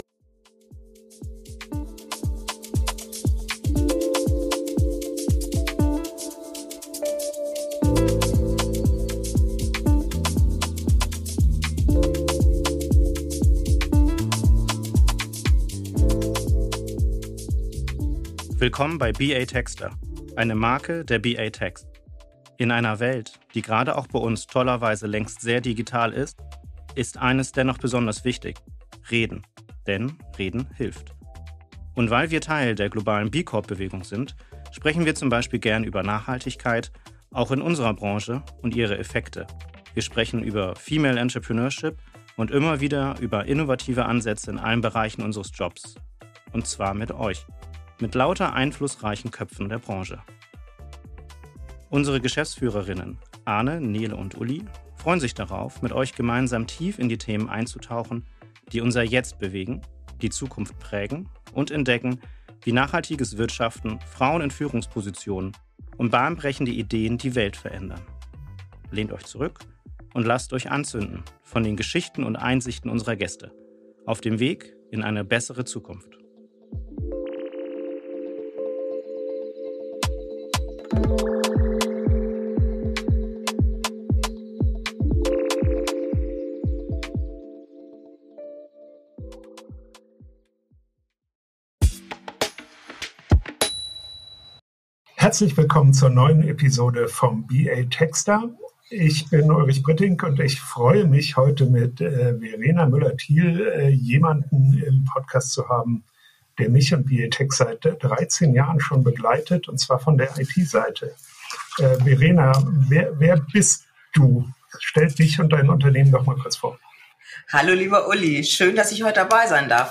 Ein Gespräch über das richtige Tempo im Wandel, über Vertrauen als Erfolgsfaktor – und über die Kanzlei 2030.